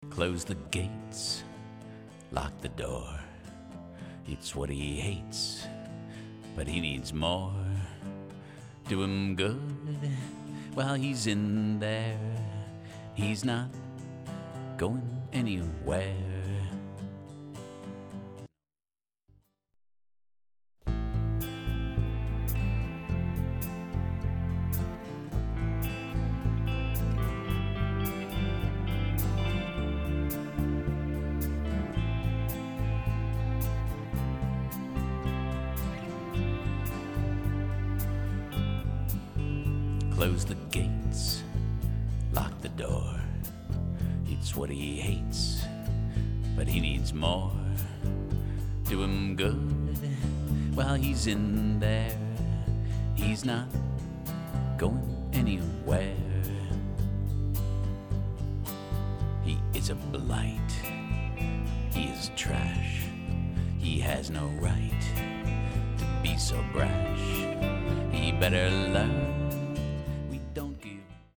Click to hear the Before and after sample
Acoustic guitar and vocal
Samples Area Music Production Sample